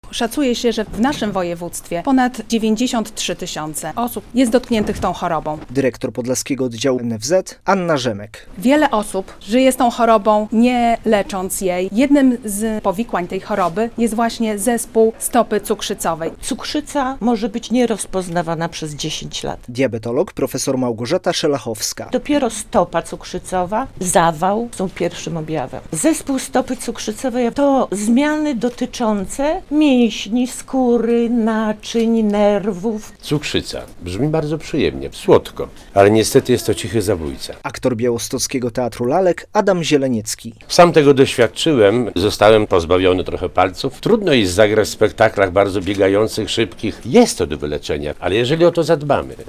Eksperci i pacjenci na temat stopy cukrzycowej - relacja